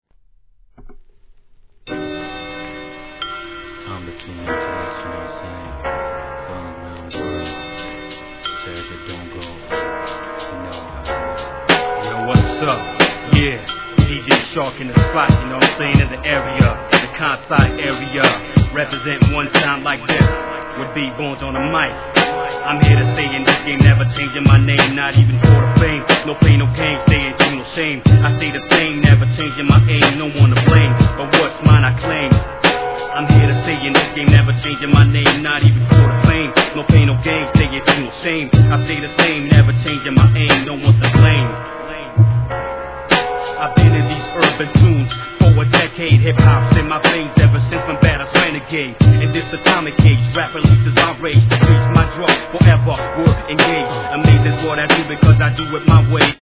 HIP HOP/R&B
このハーコーサウンドは探したアングラファンも多いいはず!!